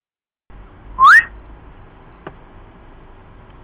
whistle